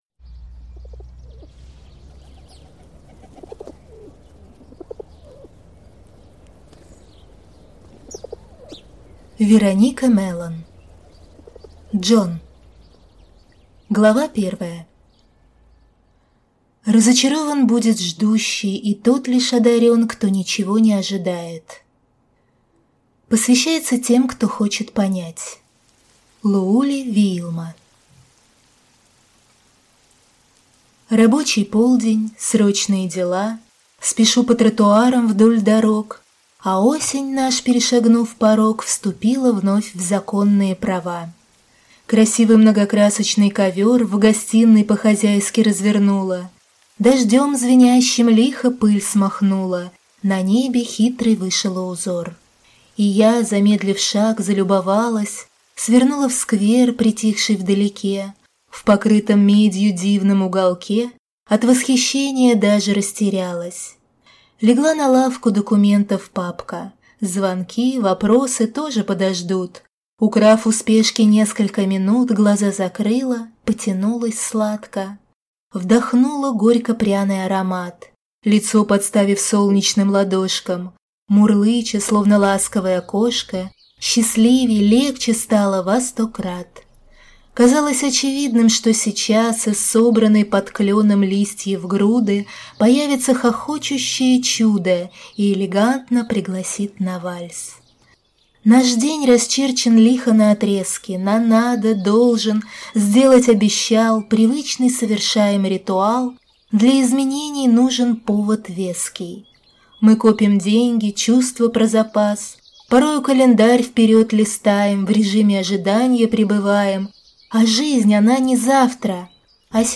Аудиокнига «Джон» в интернет-магазине КнигоПоиск ✅ в аудиоформате ✅ Скачать Джон в mp3 или слушать онлайн